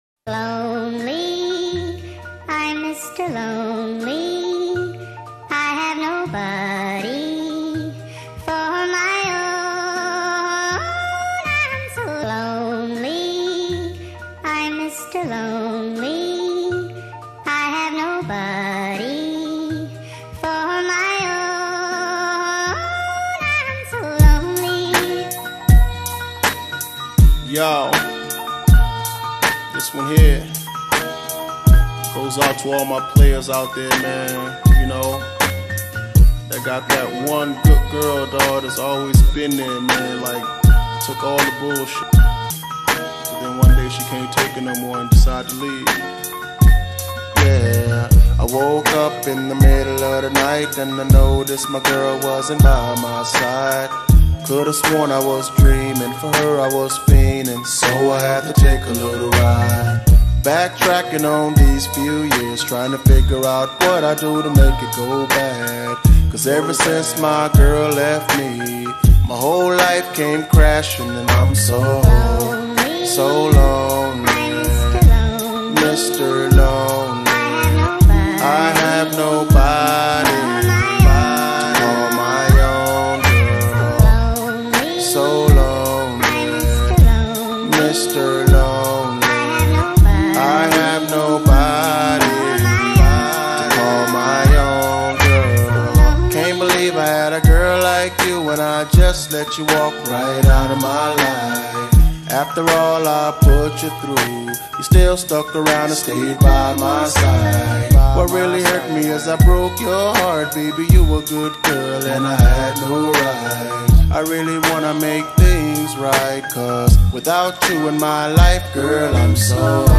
tik tok remix